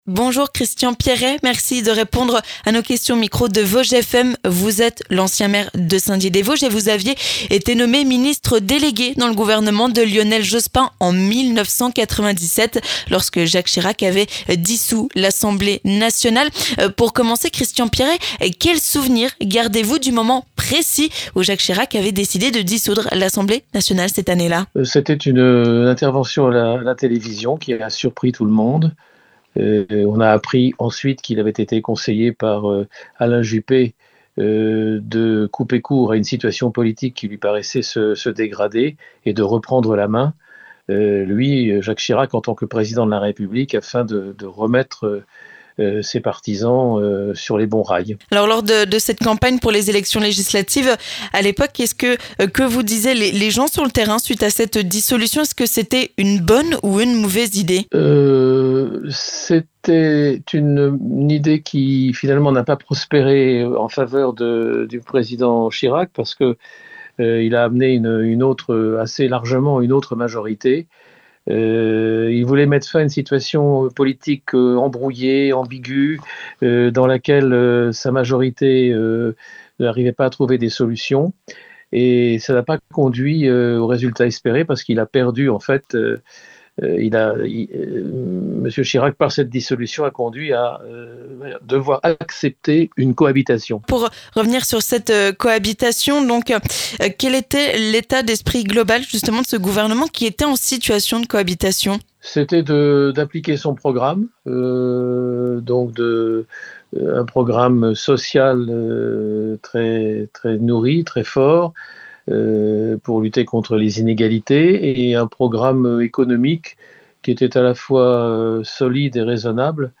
Nous avons donc souhaité rencontrer et interroger un élu vosgien qui a vécu cet événement. Christian Pierret avait été élu député de Saint-Dié-des-Vosges, puis nommé dans la foulée ministre délégué à l’Industrie.
Comment avait-il vécu cette dissolution ? Etait-ce une bonne idée ? La réponse au micro de Vosges FM avec Christian Pierret.